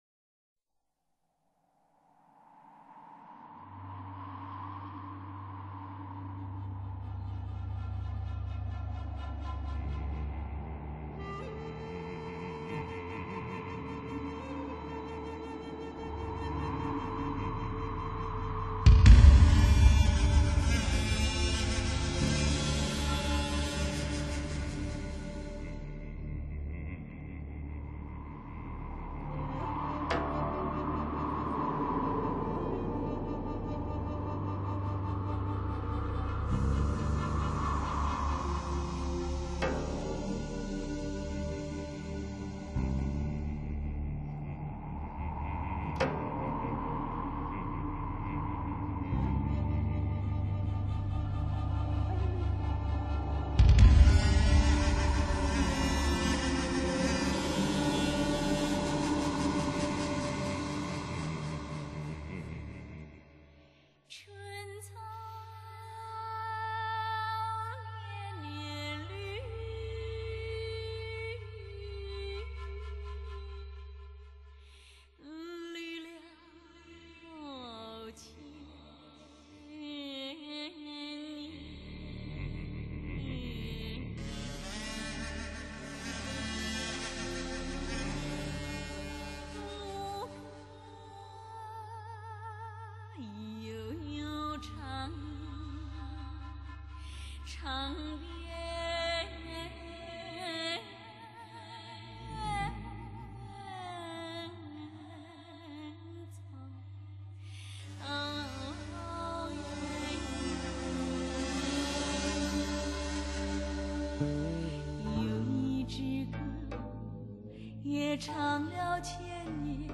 此碟音效极好。
七个段落组成，整部作品气势磅礴，演唱、录音俱佳。